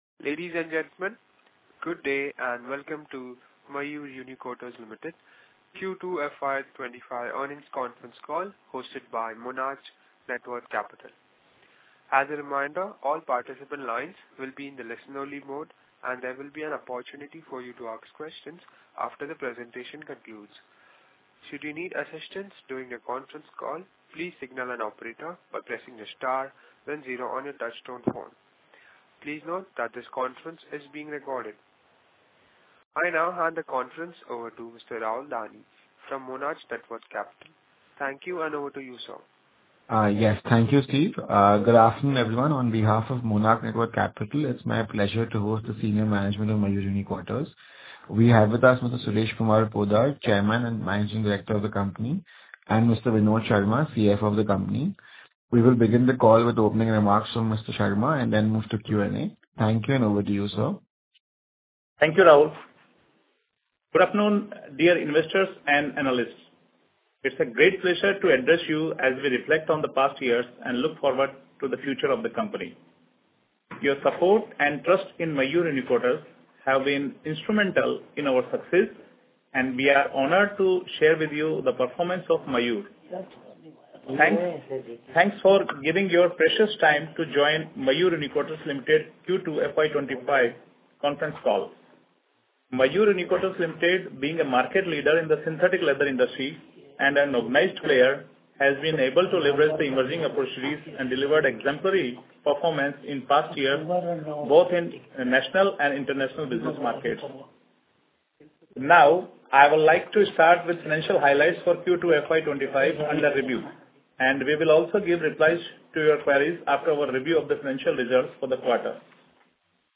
Concalls
recording-call-of-un-audited-financial-results-for-Q2fy2025.mp3